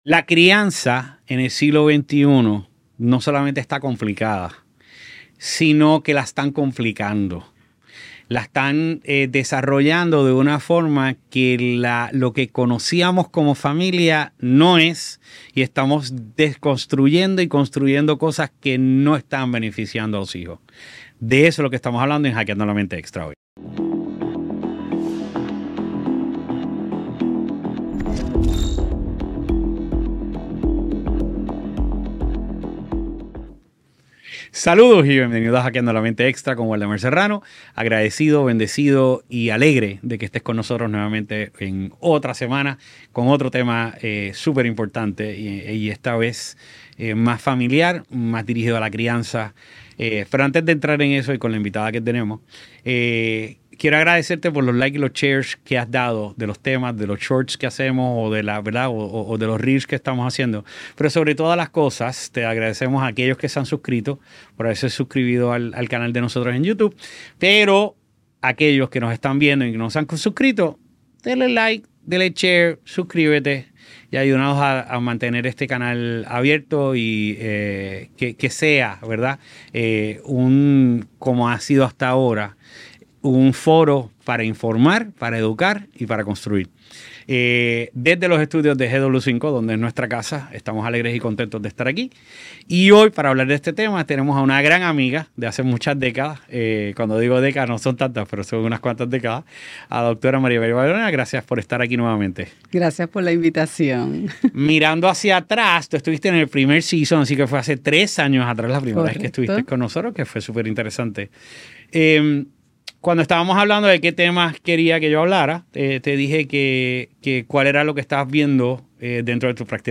A lo largo de esta conversación